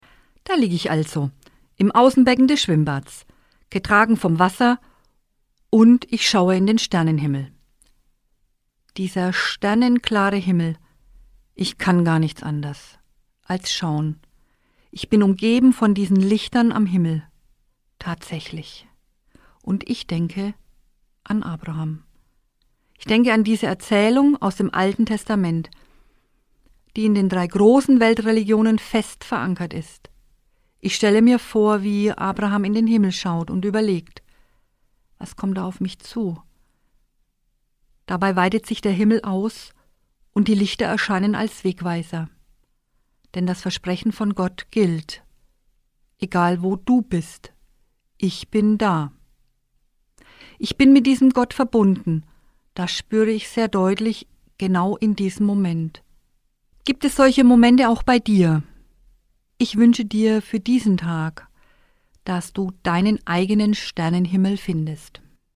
Autorin und Sprecherin ist